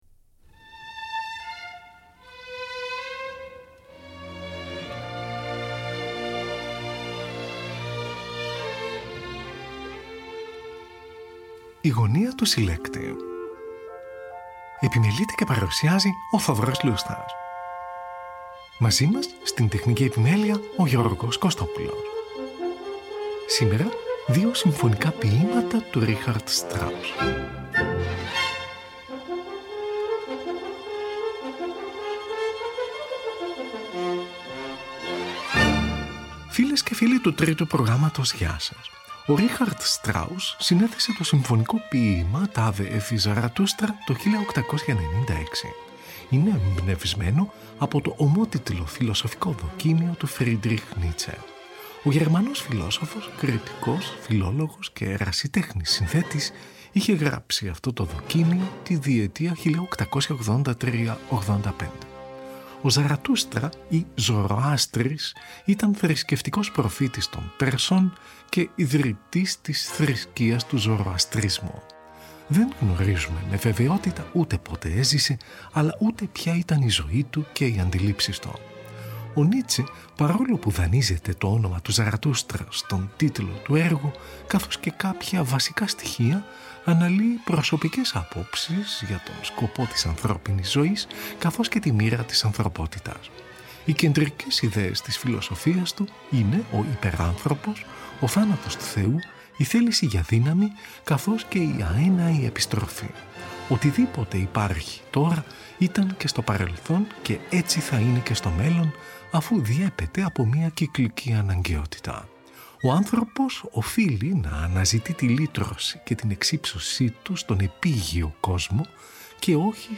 Δύο Συμφωνικά Ποιήματα
από Ιστορικές Ηχογραφήσεις